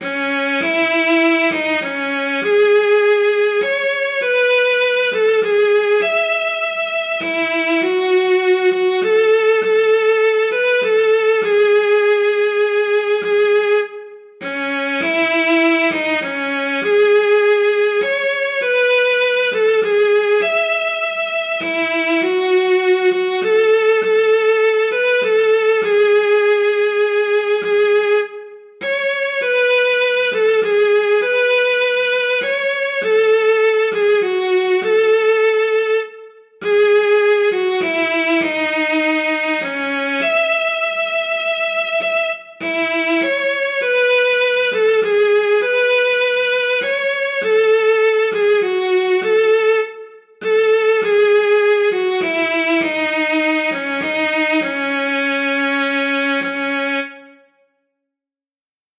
5_handprint_ballad_m2.47_st2_fiddle_leap.mp3 (2.25 MB)
Audio fiddle of transcribed recording of stanza 1, sung with leap in notes, for “Oh faine would I wive,” to “Drive the Cold Winter Away"